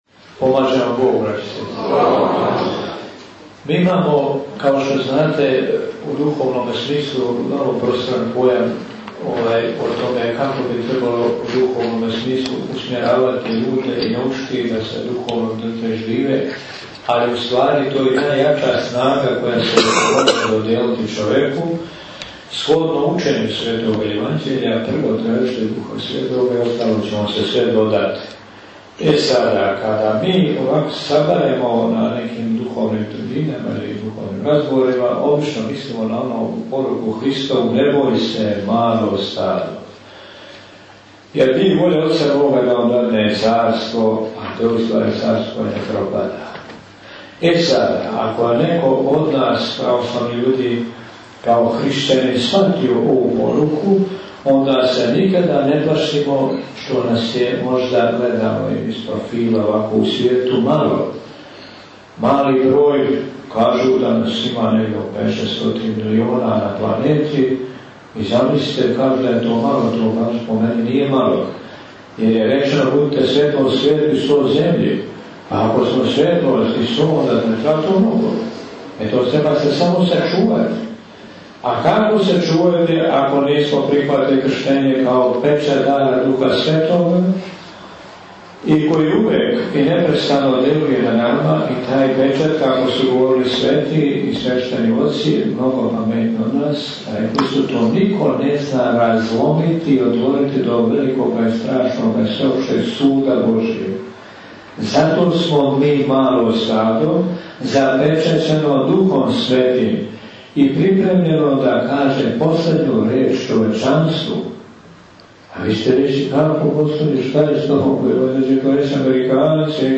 Предавања
Звучни запис 212. духовне трибине у организацији храма Светог великомученика Трифуна на Топчидерском гробљу, одржане 10. октобра у сали београдске општине Вождовац.